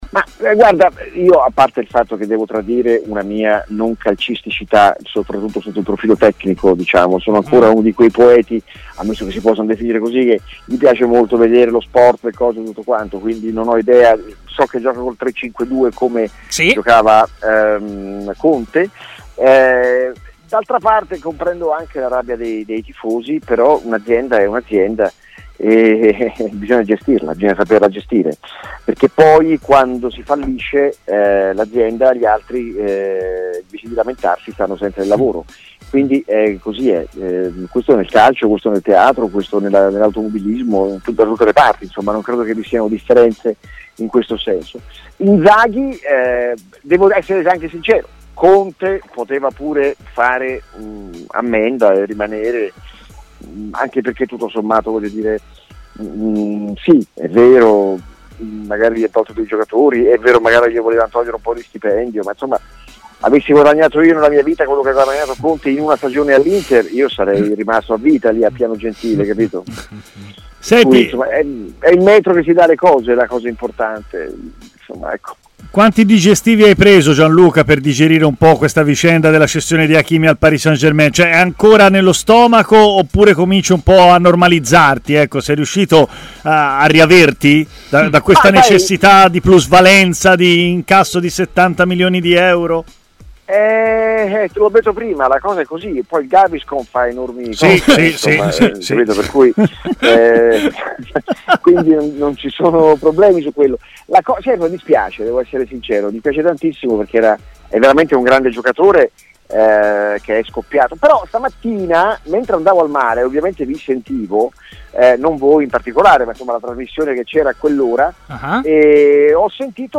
Ai microfoni di Stadio Aperto, la trasmissione di TMW Radio, Gianluca Guidi, attore teatrale interista commenta così l’addio di Conte: “Se devo essere sincero, Conte poteva rimanere!” non si sbilancia invece sull’arrivo di Simone Inzaghi- “Un’azienda è un’azienda, bisogna saperla gestire.